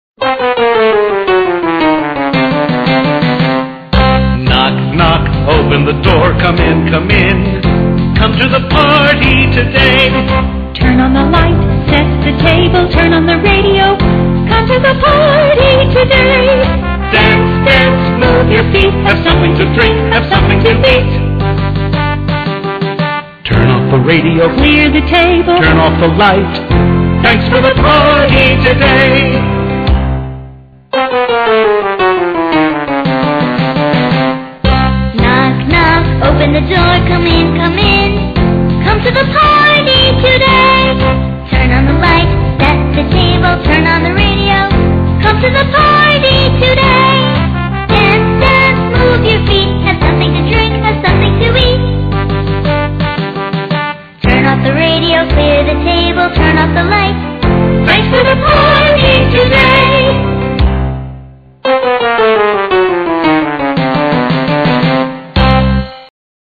在线英语听力室英语儿歌274首 第25期:Come to the Party Today的听力文件下载,收录了274首发音地道纯正，音乐节奏活泼动人的英文儿歌，从小培养对英语的爱好，为以后萌娃学习更多的英语知识，打下坚实的基础。